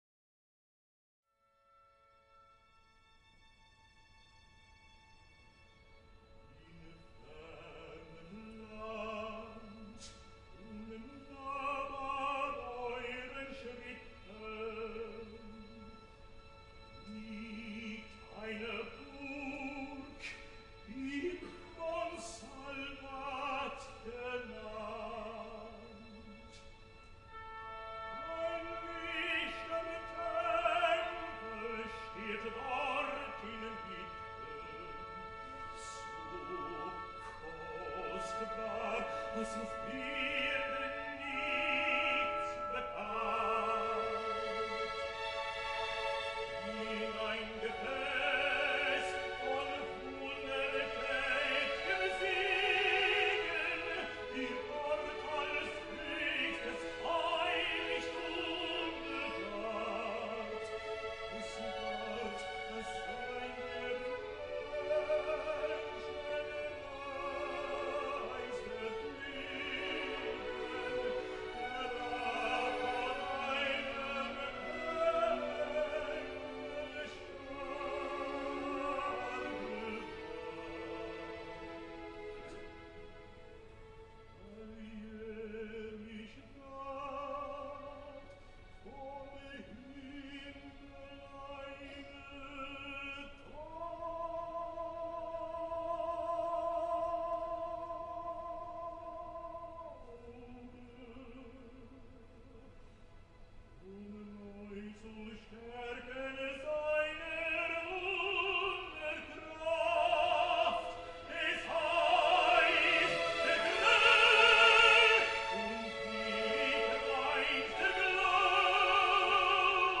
Johan Botha al Japó
Tots ells poden rivalitzar amb qualsevol dels tenors històrics, més enllà de la veu, ho canten francament bé, a mi m’agrada moltíssim la versió de Johan Botha, que també es pot veure a Youtube, però per no condicionar,. és molt millor escoltar-lo, la versió sencera és magnífica, plena de pianos i mitges veus sensacionals. les altres són prou conegudes i venerades.